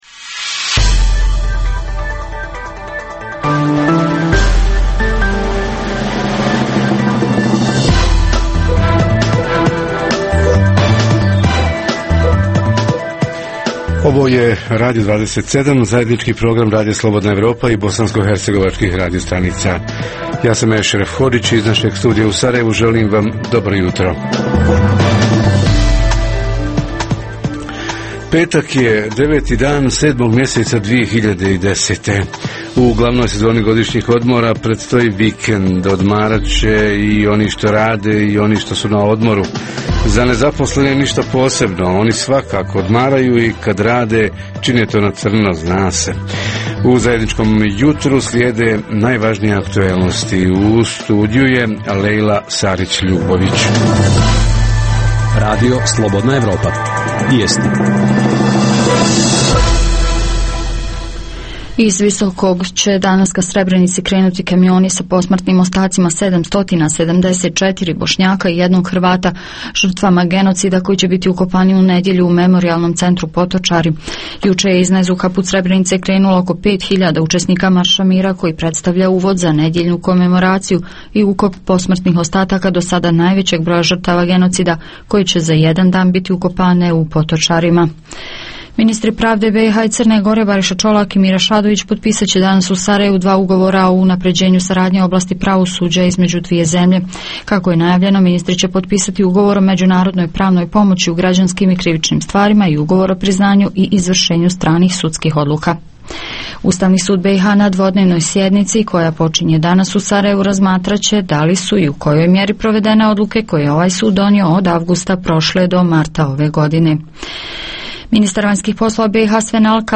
Prirodno i kulturno-historisko nasljeđe – ko i koliko ulaže u njegovo očuvanje i zaštitu i koliko je to nasljeđe u funkciji razvoja turizma? Reporteri iz cijele BiH javljaju o najaktuelnijim događajima u njihovim sredinama.
Redovni sadržaji jutarnjeg programa za BiH su i vijesti i muzika.